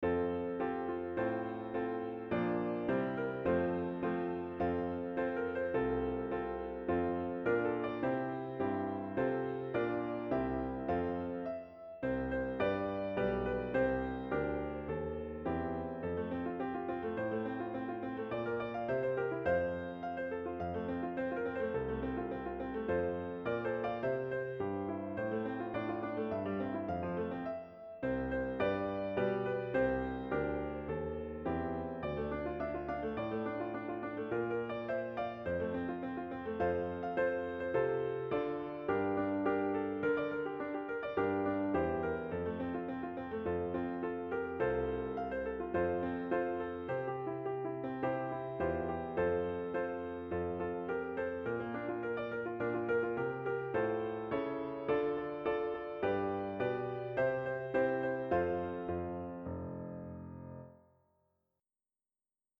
Roomrama is one of two de facto national anthems of the Assyrian people.